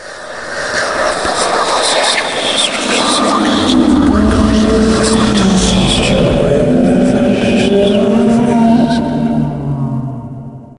zombie_idle_2.ogg